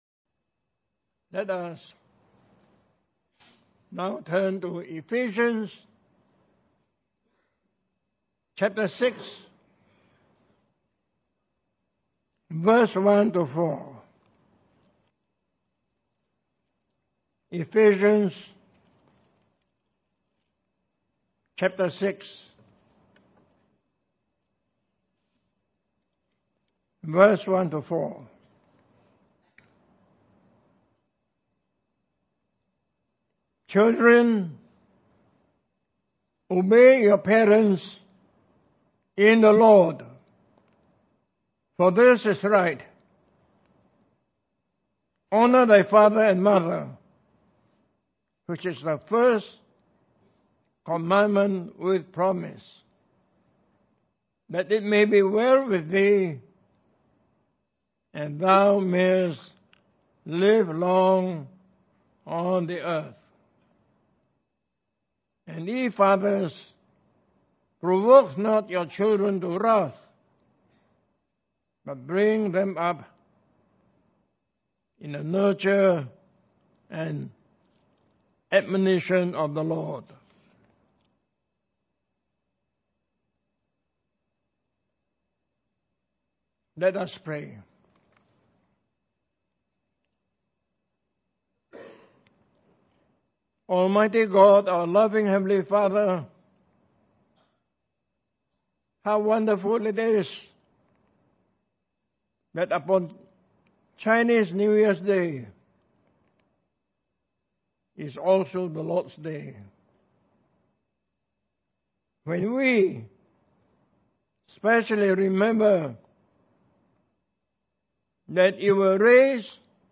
In this sermon, the preacher announces his upcoming anniversary message on the role of a mother in a Christian home. He believes that Chinese New Year is a great opportunity to promote Christian filial piety and parental love. The sermon is based on Ephesians 6:1-4, which emphasizes the importance of children obeying their parents and fathers not provoking their children.